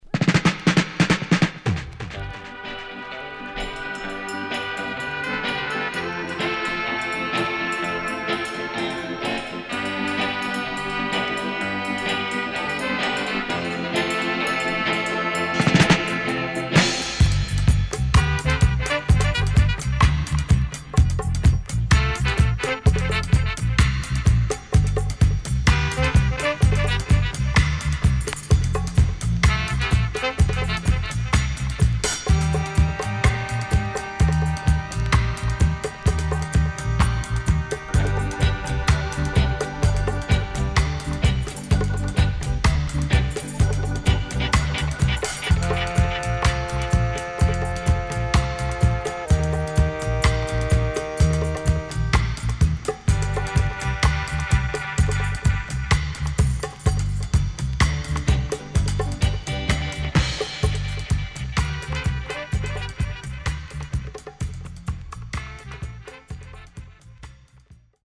SIDE A:盤質は良好です。盤面は少しうすいこまかい傷ありますがきれいです。